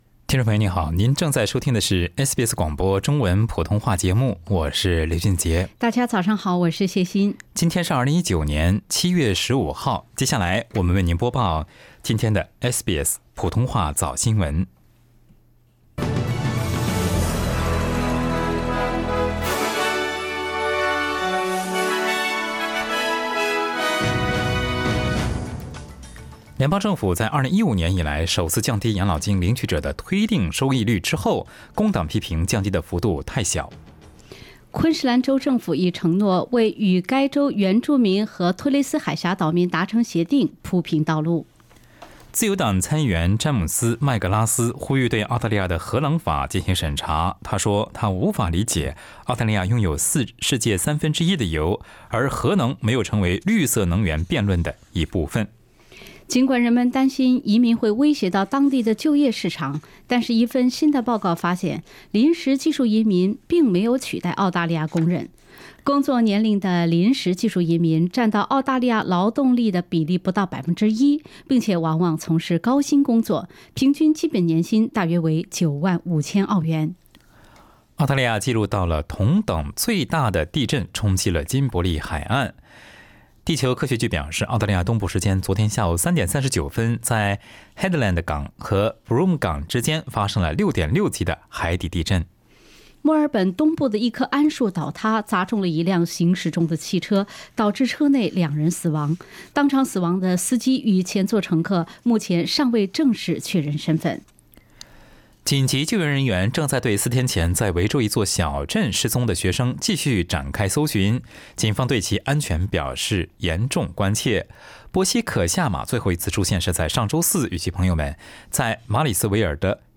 SBS早新闻(7月15日）
SBS Chinese Morning News Source: Shutterstock